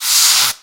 extinguisher.1.ogg